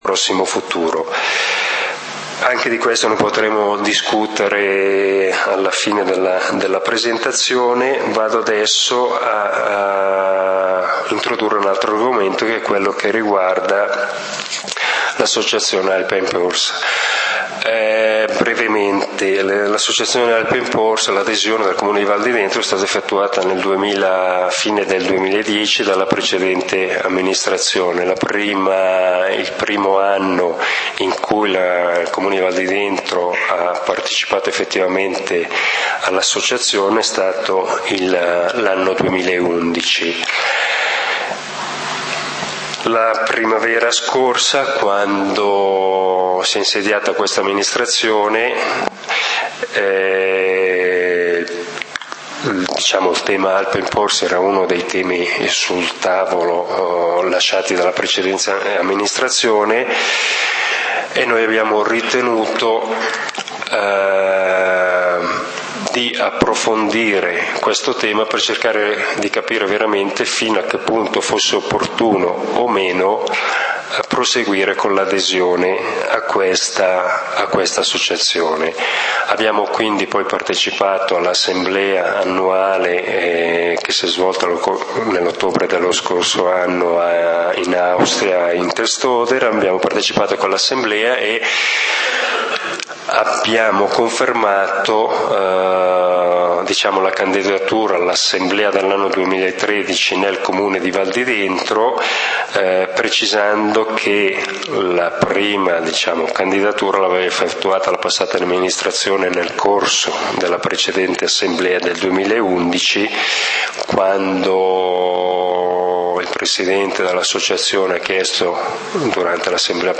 Turismo: informazione, confronto ed approfondimento Assembrela pubblica del comunale di Valdidentro del 14 Ottobre 2013